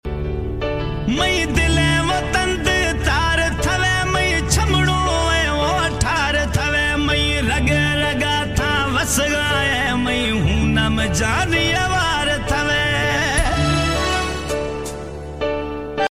Jacobabad city Airport side sound effects free download